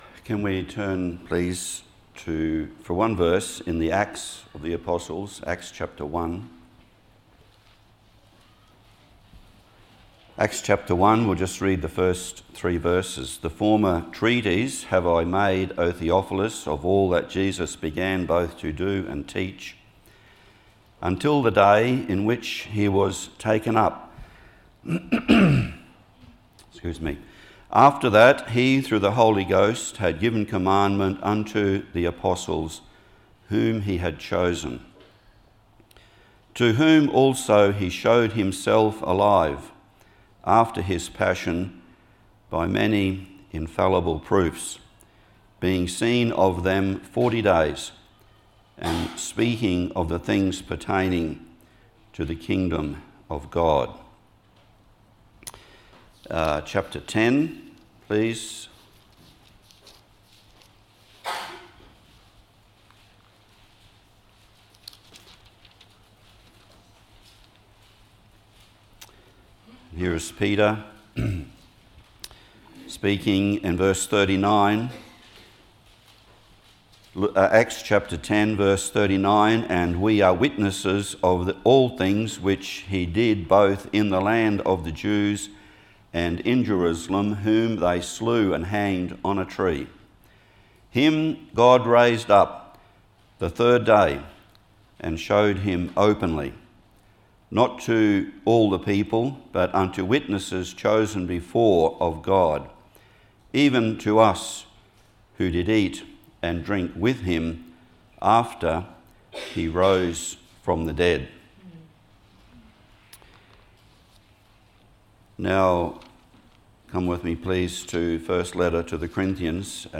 This message focuses on the below headings: Scripture readings: Acts ch1:1-3; ch10:39-41 1 Cor 15:12, 20, 35, 51-58 Location: Cooroy Gospel Hall (Cooroy, QLD, Australia)